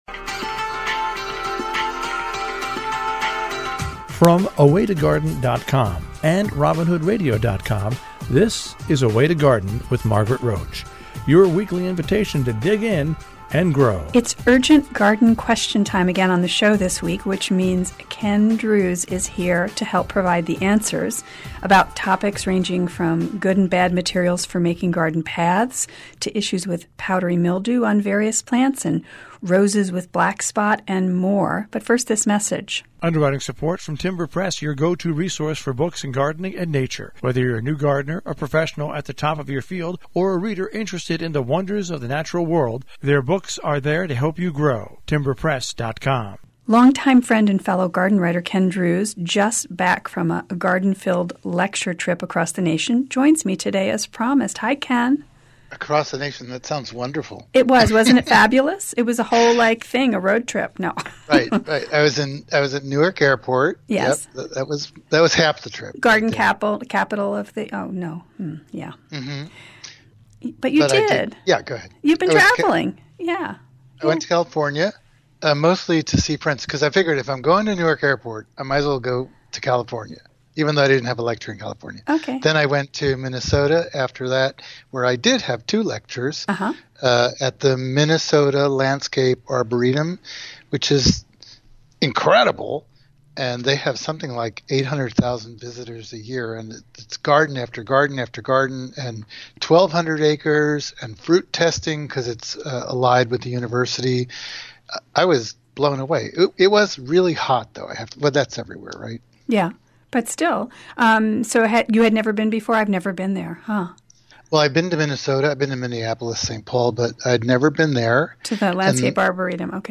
Q&A - A Way to Garden